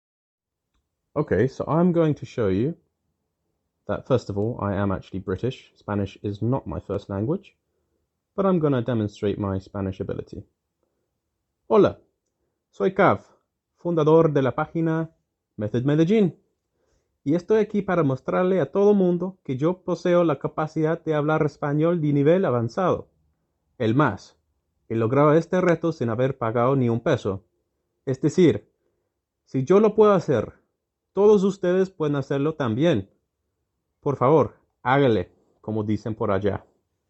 For the accent - el acento paisa pues!
Here’s how my Spanish sounds: